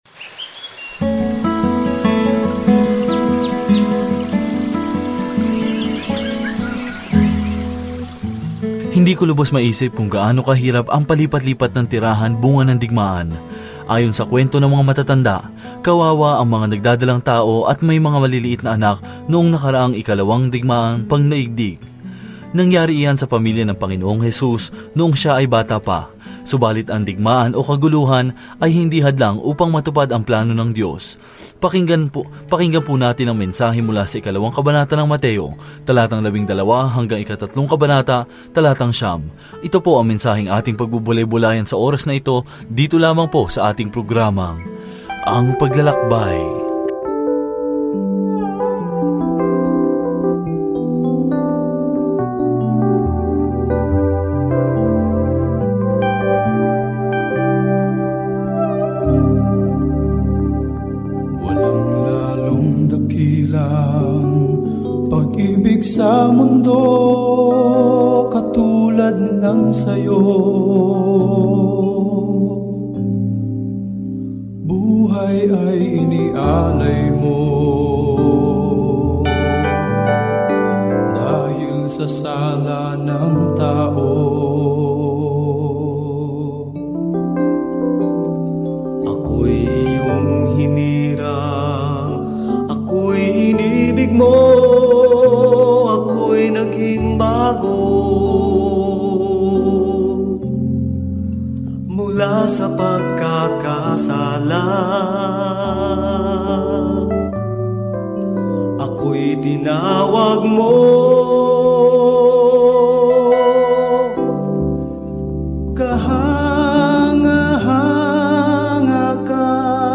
Banal na Kasulatan Mateo 2:12-23 Mateo 3:1-9 Araw 4 Umpisahan ang Gabay na Ito Araw 6 Tungkol sa Gabay na ito Pinatunayan ni Mateo sa mga Judiong mambabasa ang mabuting balita na si Jesus ang kanilang Mesiyas sa pamamagitan ng pagpapakita kung paano natupad ng Kanyang buhay at ministeryo ang hula sa Lumang Tipan. Araw-araw na paglalakbay sa Mateo habang nakikinig ka sa audio study at nagbabasa ng mga piling talata mula sa salita ng Diyos.